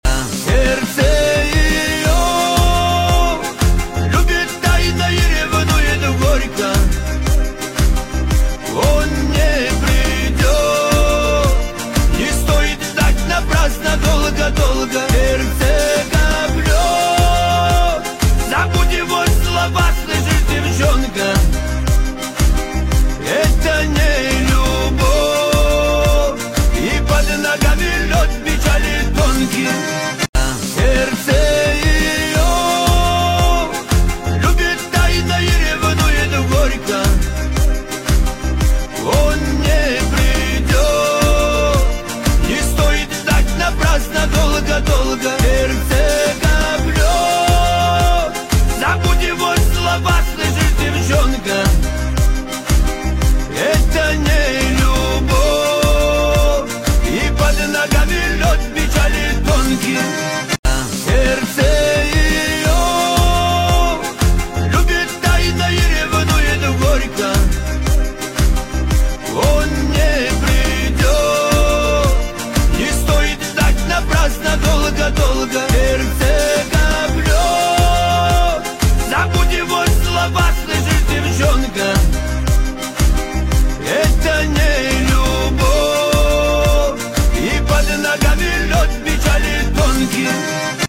Плейлисты: Кавказские песни 2025